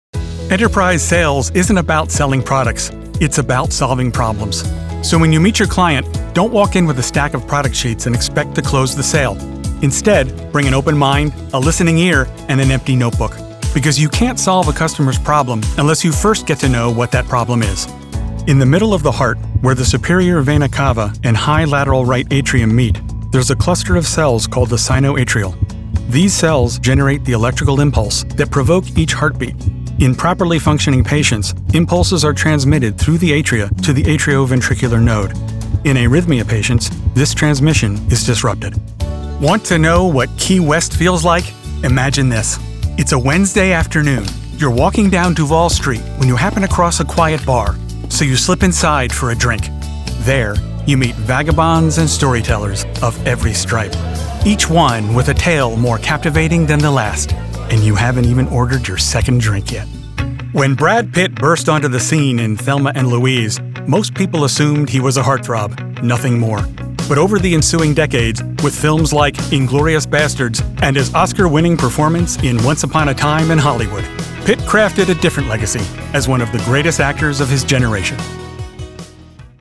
Narration Demo
English - USA and Canada
Middle Aged
Senior